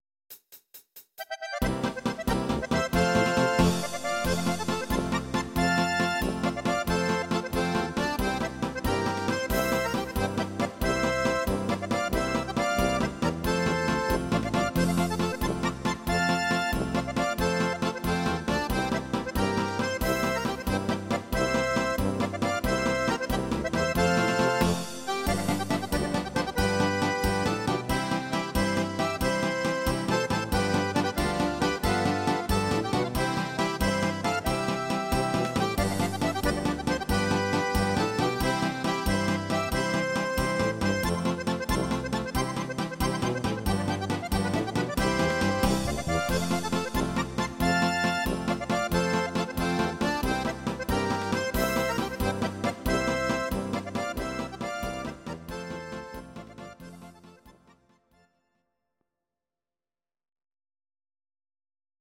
instr. steirische Harmonka